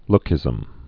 (lkĭzəm)